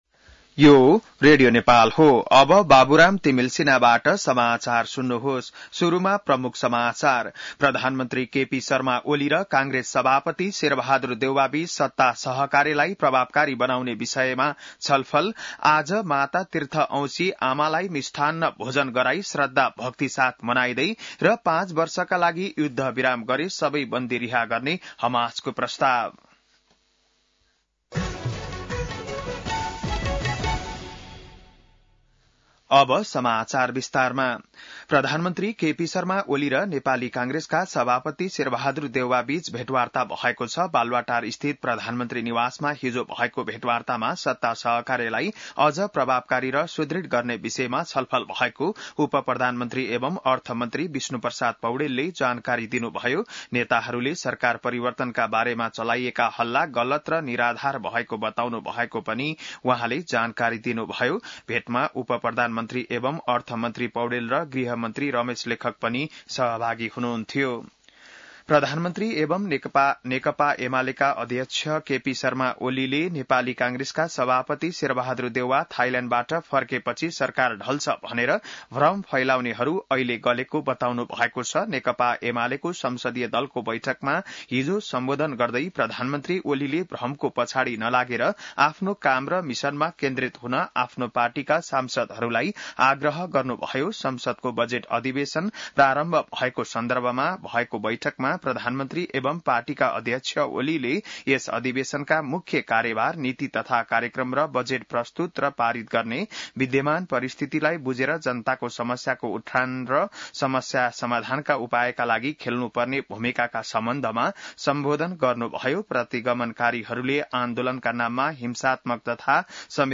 बिहान ९ बजेको नेपाली समाचार : १४ वैशाख , २०८२